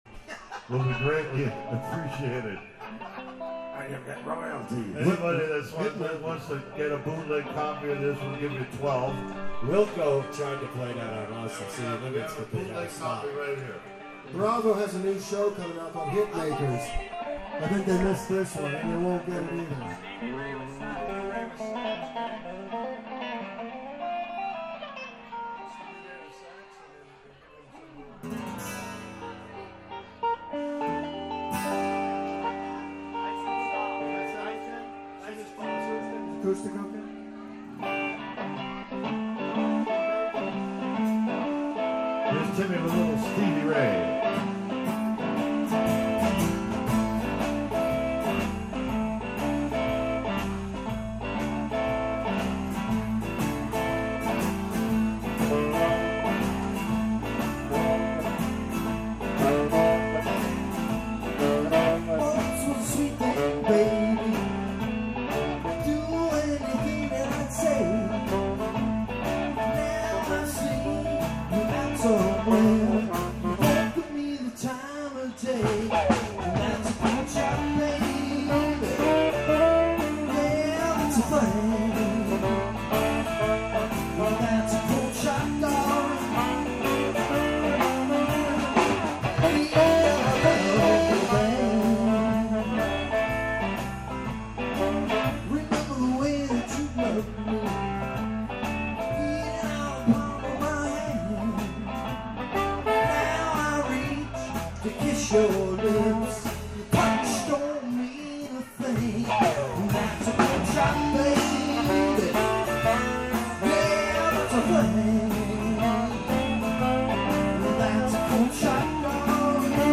vocals & piano
guitar & vocals
horns, piano & vocals
bass, piano & vocals
drums
lead guitar & vocals
Live music on the deck at Molly's May 24 2012